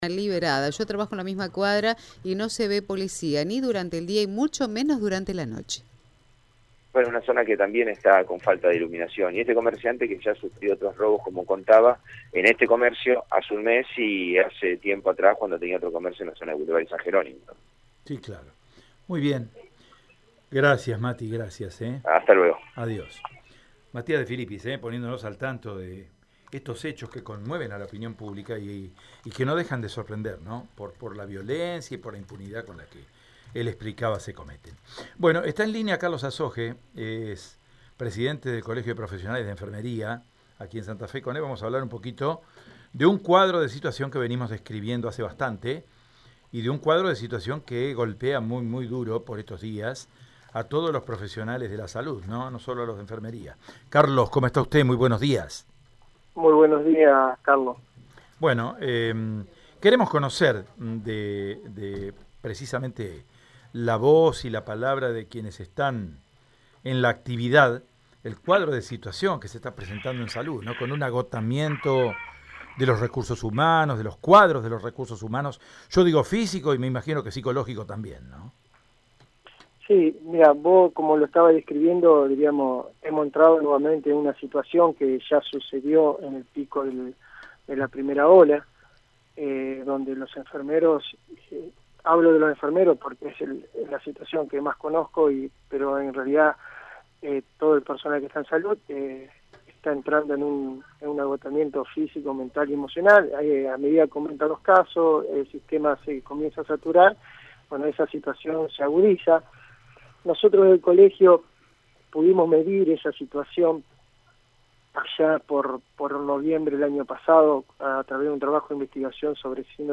En este sentido, en diálogo con Radio EME